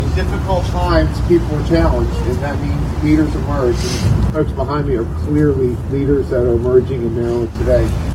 Former Maryland Governor Bob Ehrlich at Maryland’s First Freedom Caucus
Former Maryland governor Bob Ehrlich was present for Maryland’s launch as the thirteenth state to join the State Freedom Caucus Network on Tuesday, January 14.  Governor Ehrlich expressed his appreciation for those involved in Maryland’s branch of the State Freedom Caucus during these challenging times…